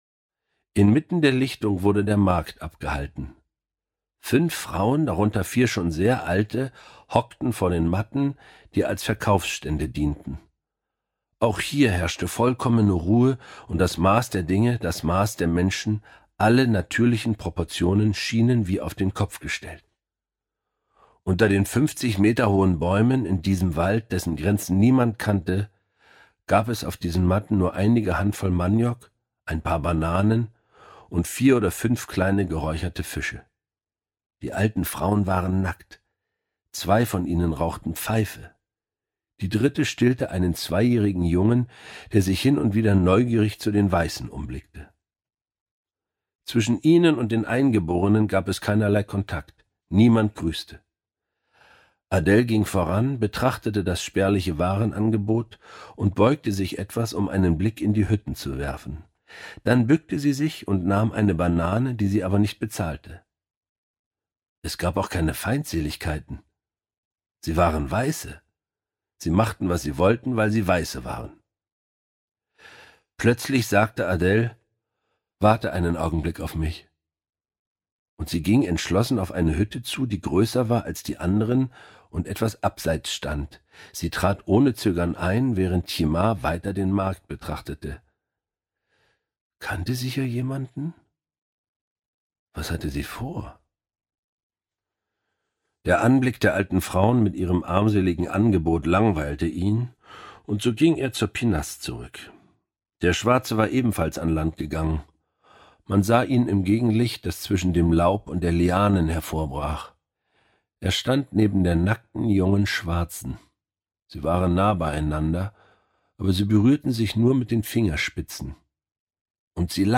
Tropenkoller Georges Simenon (Autor) Charly Hübner (Sprecher) Audio-CD 4 CDs (4h 53min) Ausstattung: mit Booklet 2020 | 1.